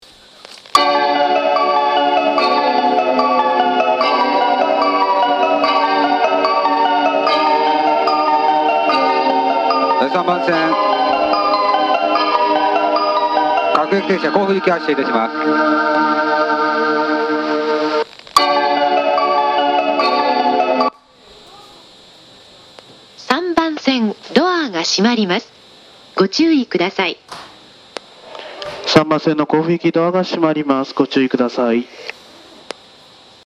発車メロディー
1.1コーラスです。
余韻切りが多く、2コーラス目は少し厳しいです。